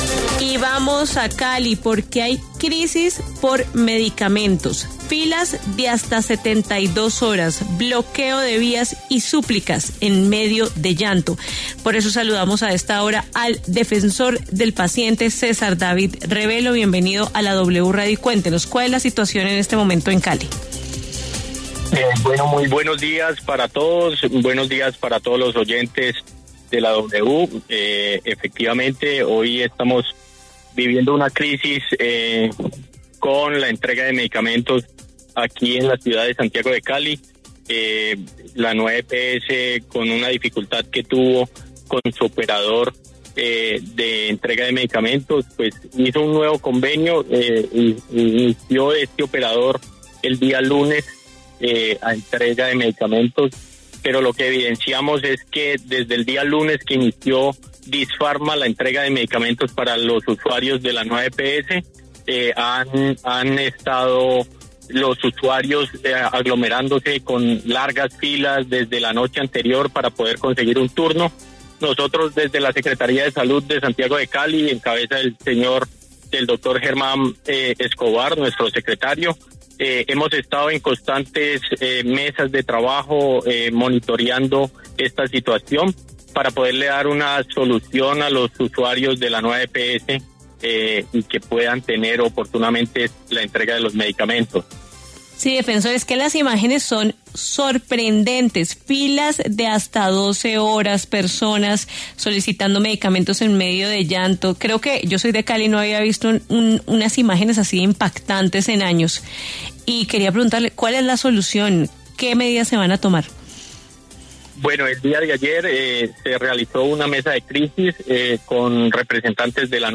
César Revelo, defensor del Paciente designado por la Secretaría de Salud, se refirió en W Fin de Semana a la falta de entrega de medicamentos por parte del dispensario Disfarma a los usuarios de la Nueva EPS.